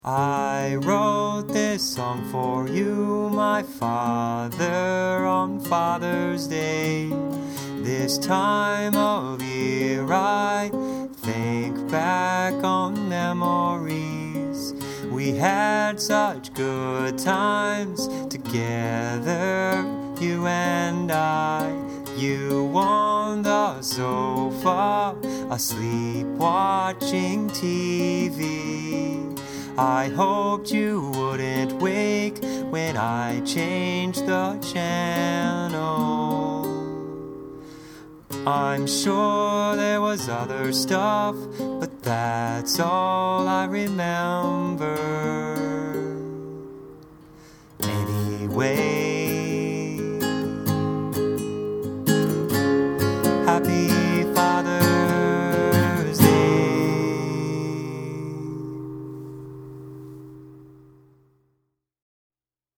This is still a rough demo, but it should communicate the idea well enough. I reminisce about that hallowed father and son bonding moment: sitting on the couch while he sleeps and wondering if I can get the remote away from him without waking him up.